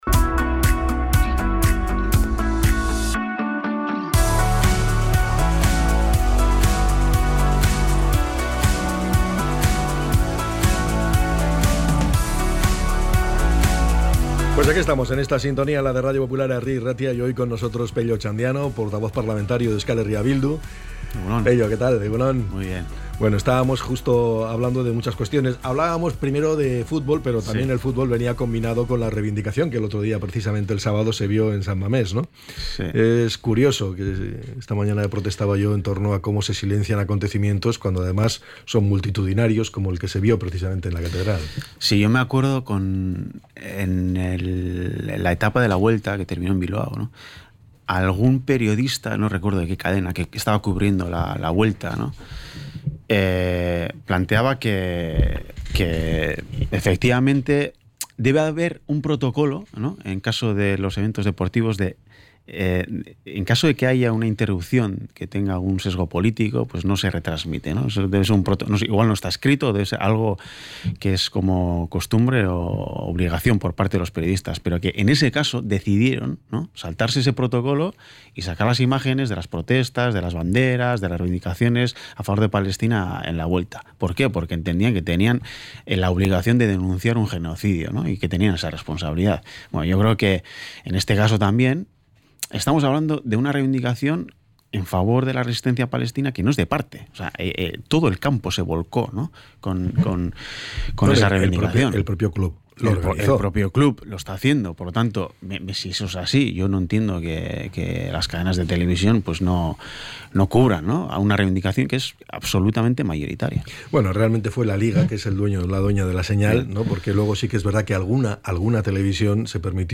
ENTREV.-PEIO-OTXANDIANO.mp3